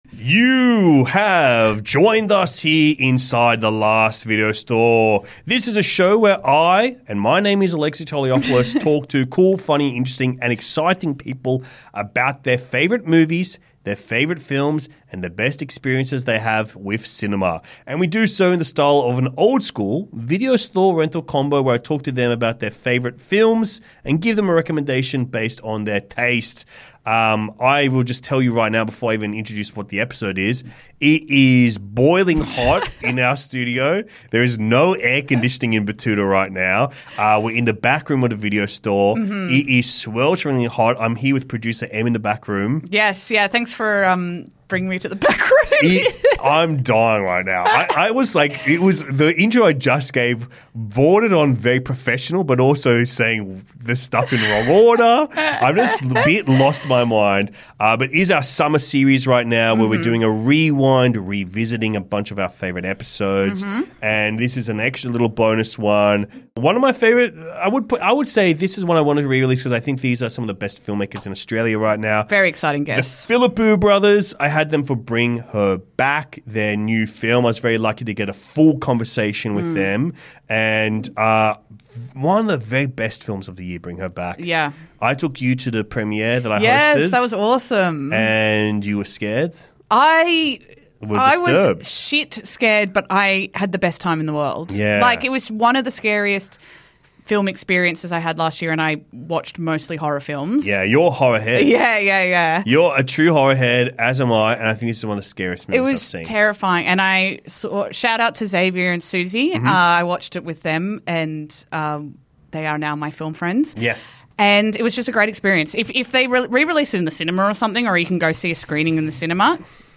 We’re revisiting our hilarious and insightful chat with filmakers and twin brothers Danny and Michael Philippou! Their latest film, BRING HER BACK is an emotionally resonant horror exploration of grief and loss that, in many ways, surpasses their remarkable debut.